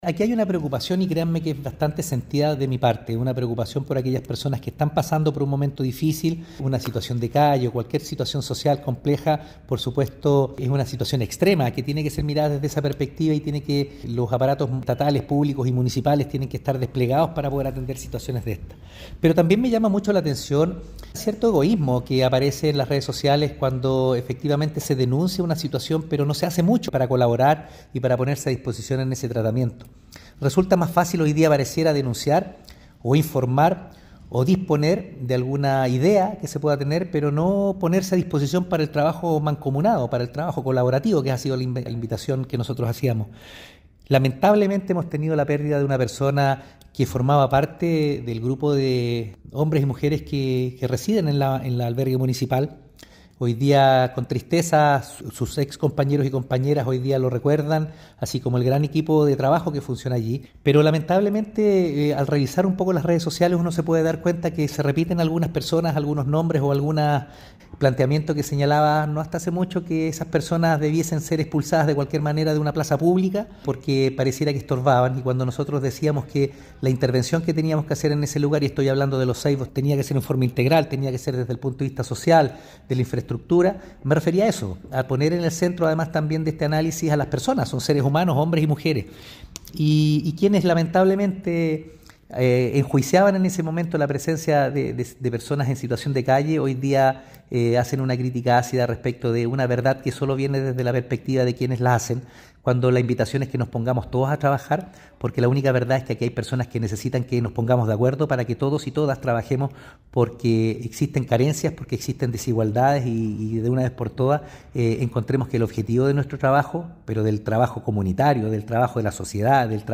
Alcalde-Oscar-Calderon-Sanchez-1-1.mp3